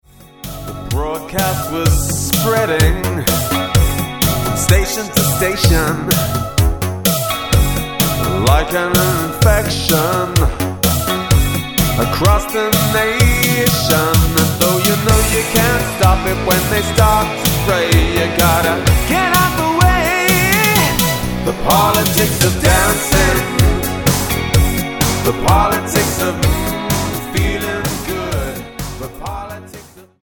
Tonart:F# Multifile (kein Sofortdownload.